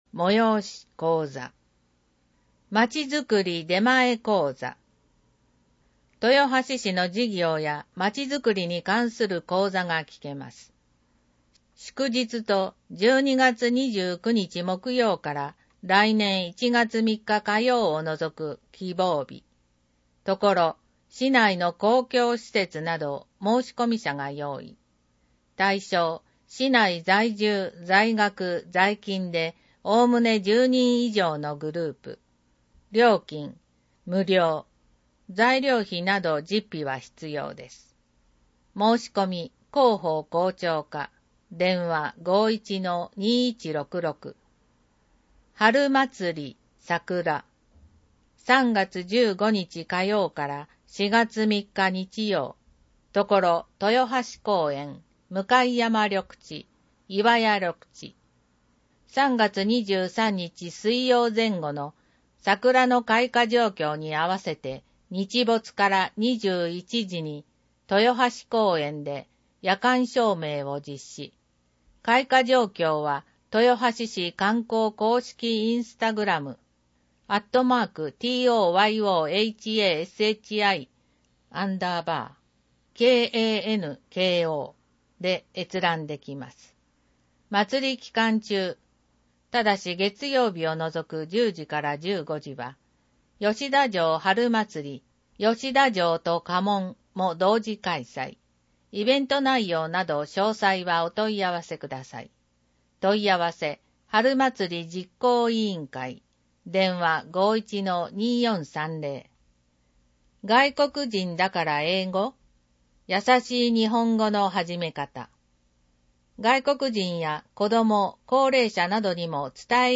• 「広報とよはし」から一部の記事を音声でご案内しています。視覚障害者向けに一部読み替えています。
（音声ファイルは『音訳グループぴっち』提供）